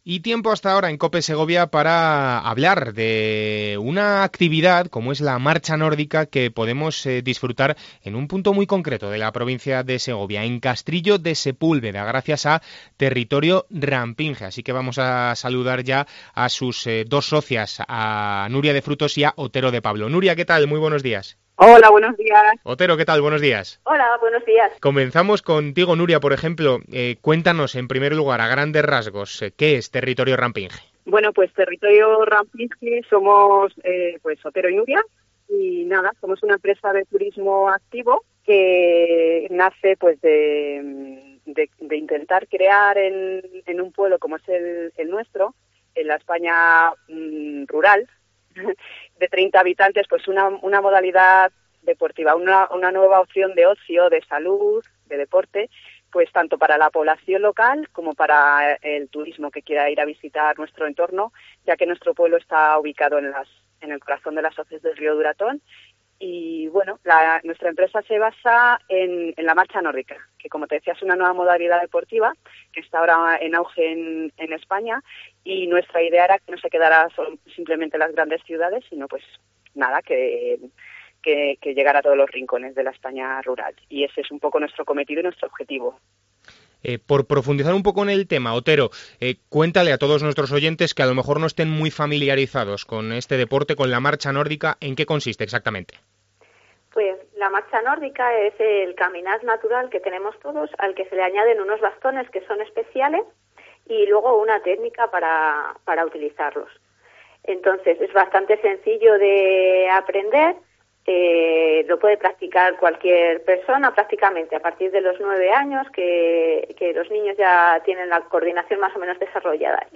Entrevista Territorio Rampinge